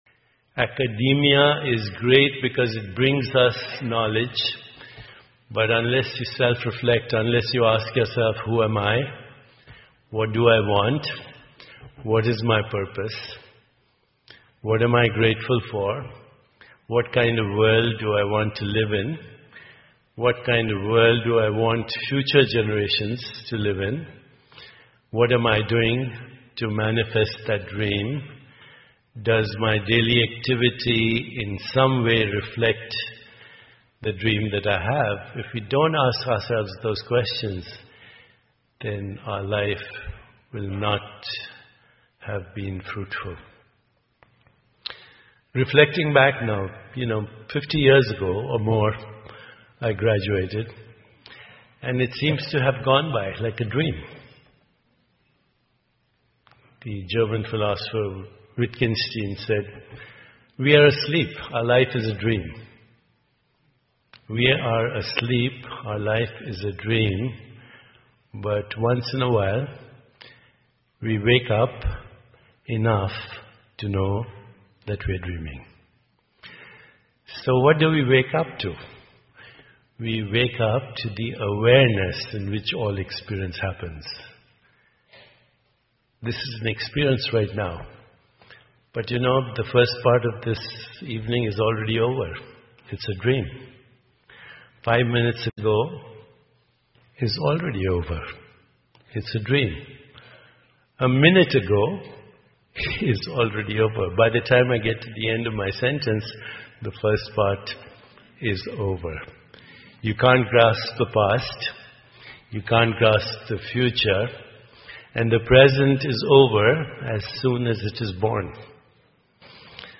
英文演讲录 狄巴克·乔布拉：成功学之感受当下(2) 听力文件下载—在线英语听力室